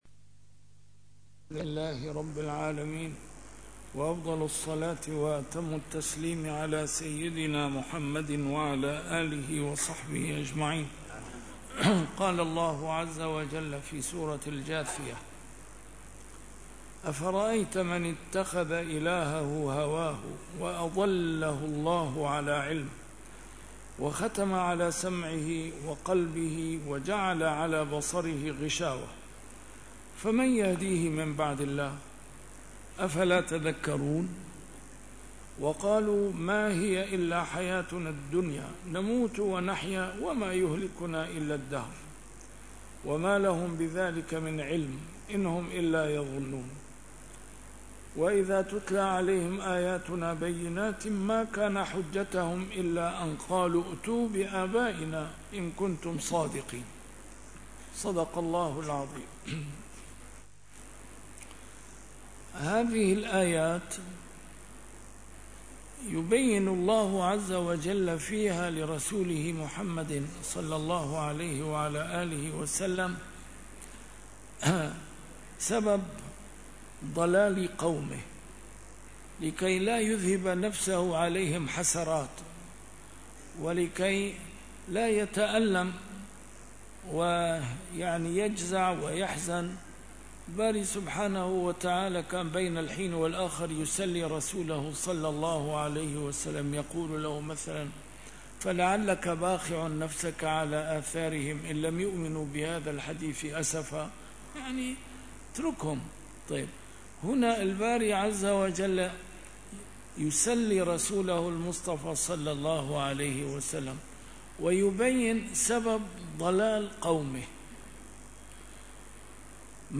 A MARTYR SCHOLAR: IMAM MUHAMMAD SAEED RAMADAN AL-BOUTI - الدروس العلمية - تفسير القرآن الكريم - تسجيل قديم - الدرس 613: الجاثية 23-25